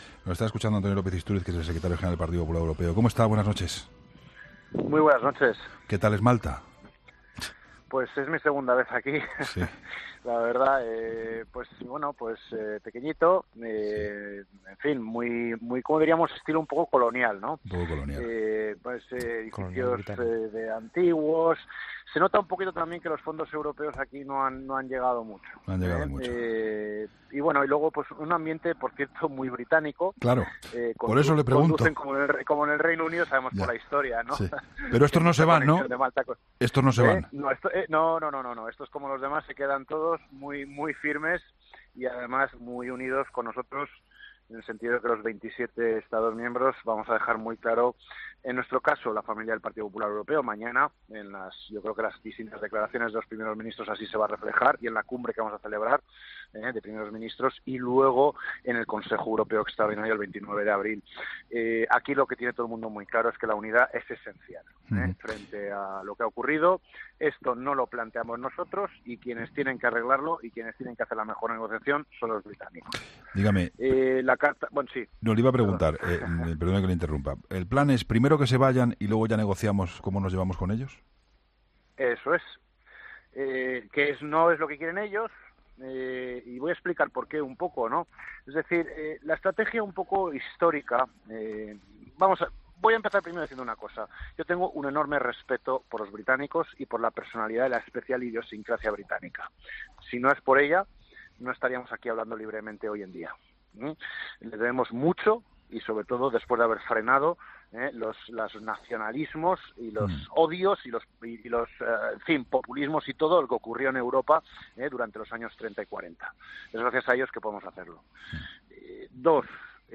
Entrevista a Antonio López-Istúriz